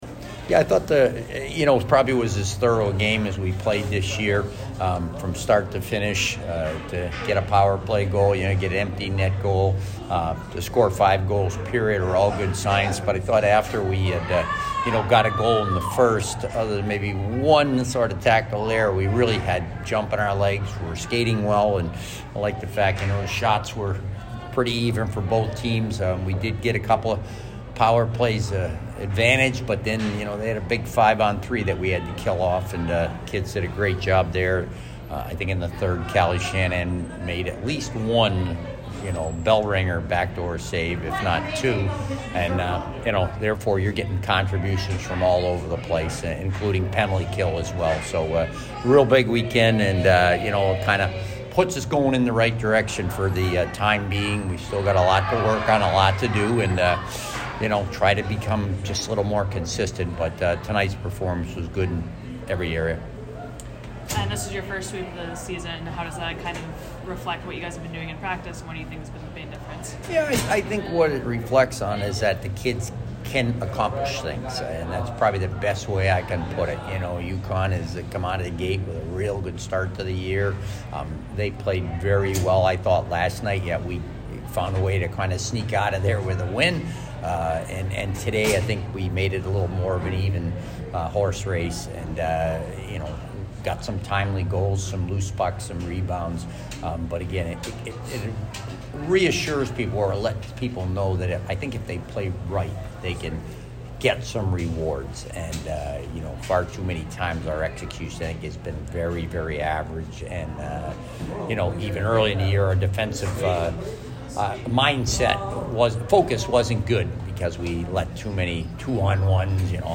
UConn Postgame Interview